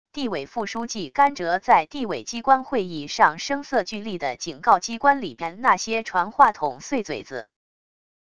地委副书记甘哲在地委机关会议上声色俱厉的警告机关里边那些传话筒碎嘴子wav音频